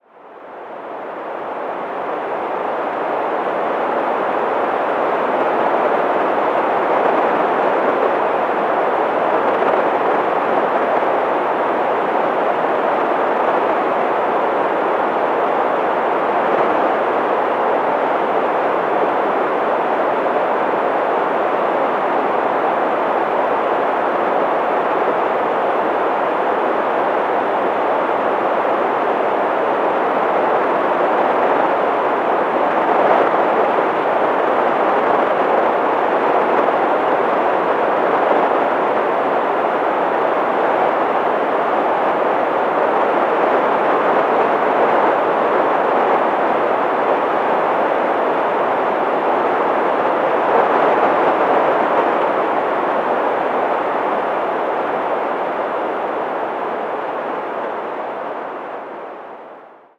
The bursts individuate.
Here is a one minute audio extract of the so-called wild Jovian electromagnetic surf recorded live May 29, 2007 at 0937 UT. at 21.1 MHz
This is a subtle specimen.   Jupiter becomes evident midway through as a "surf" and pulsation that briefly rises above the continuum.